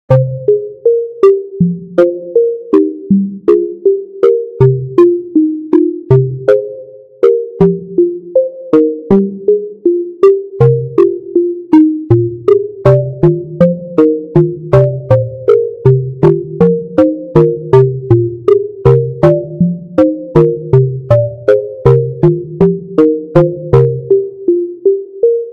ダンジョン。音の響く地下洞窟のイメージ。ループ対応。